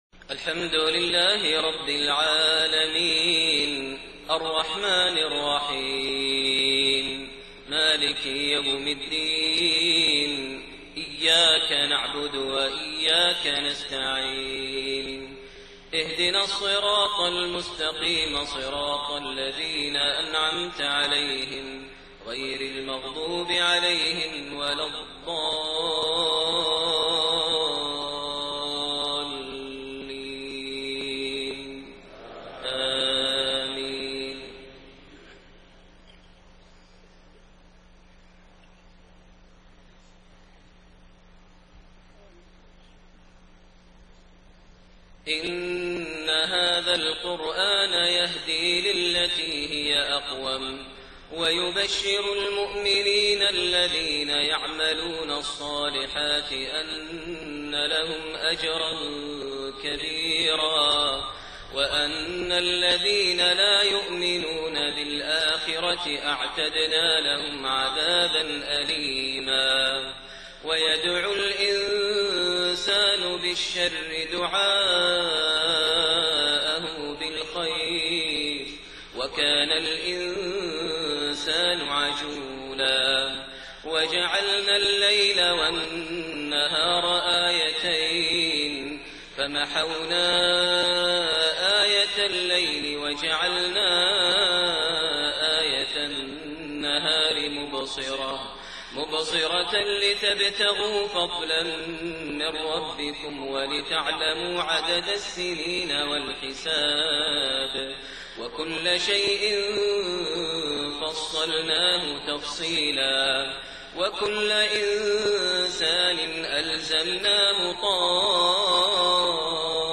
Isha prayer from Surat Al-Israa > 1429 H > Prayers - Maher Almuaiqly Recitations